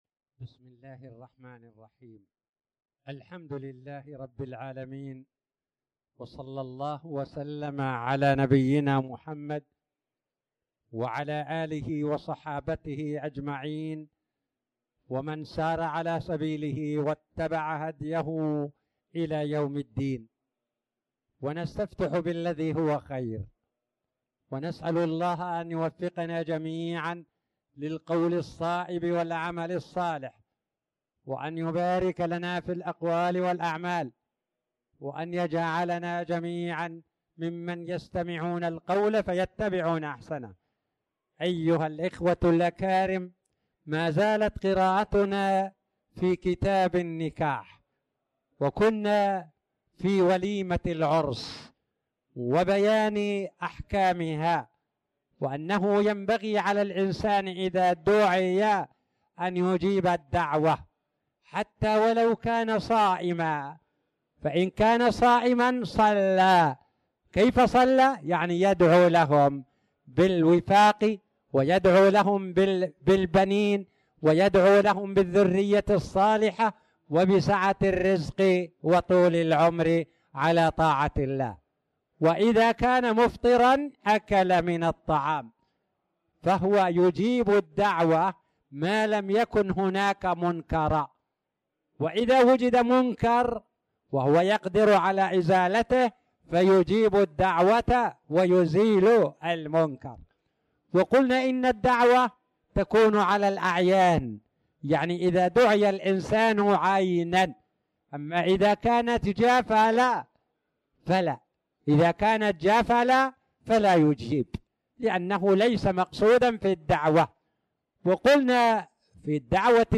تاريخ النشر ١٧ جمادى الأولى ١٤٣٨ هـ المكان: المسجد الحرام الشيخ